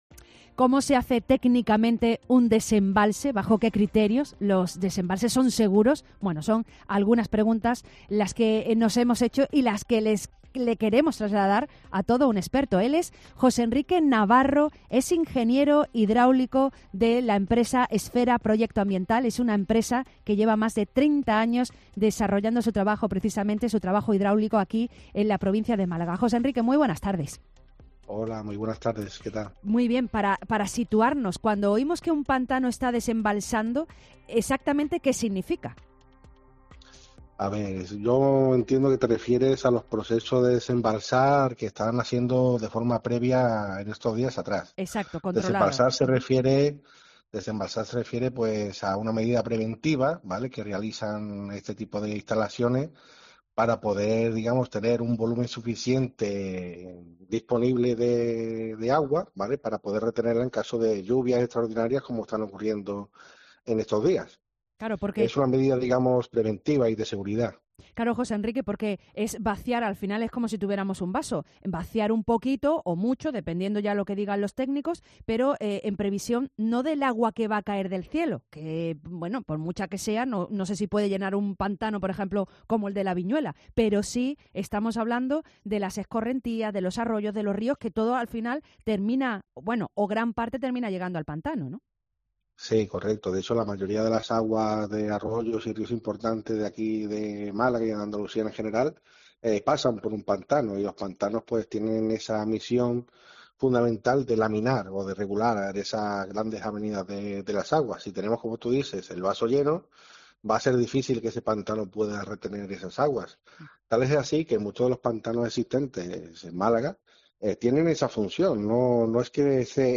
Entrevista ingeniero Hidráulico sobre cómo desembalsan los pantanos